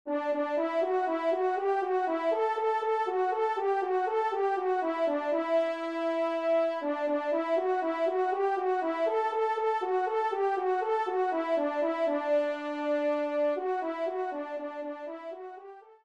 Trompe (Solo, Ton simple)